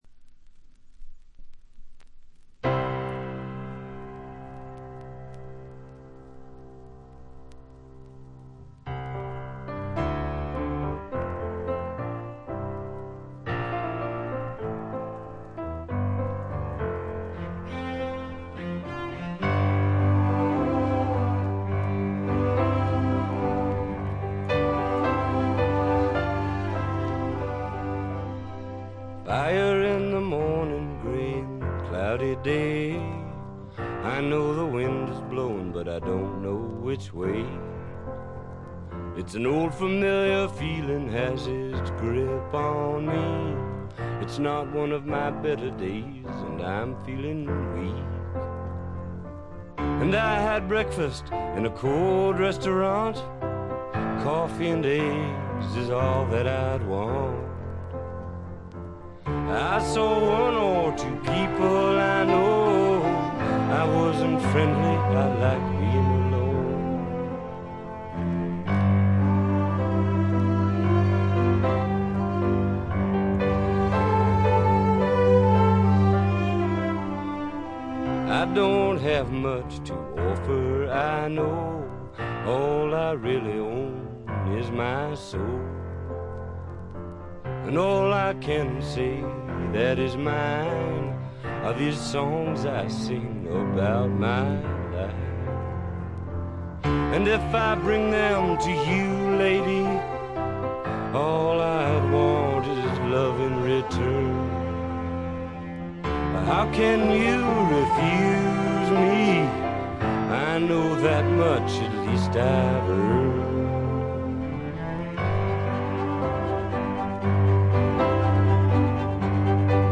B2中盤でプツ音1回。
試聴曲は現品からの取り込み音源です。